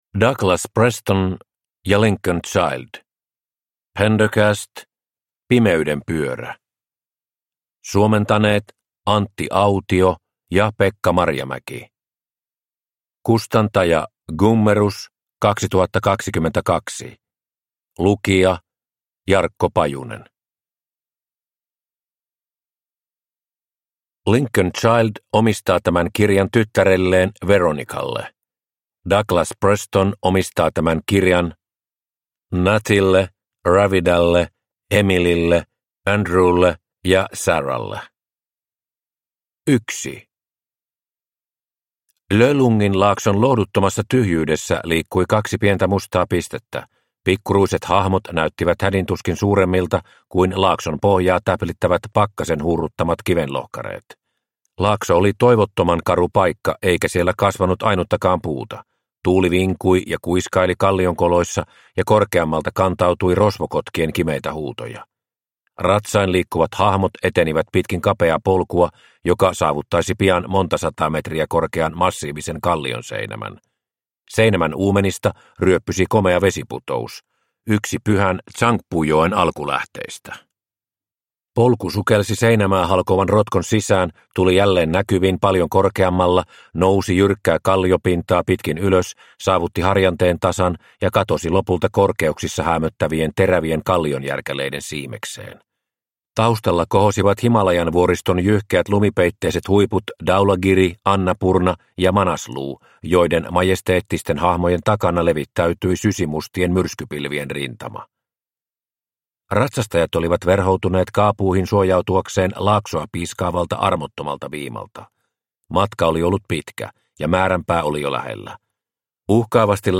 Pimeyden pyörä – Ljudbok – Laddas ner